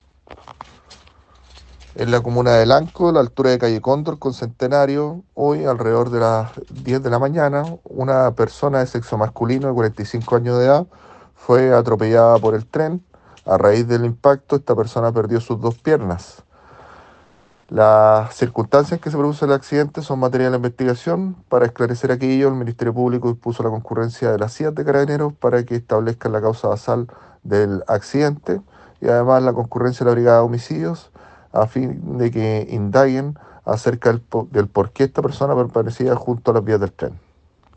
Fiscal Pierre Neira ..
Cuna-del-fiscal-Pierre-Neira.m4a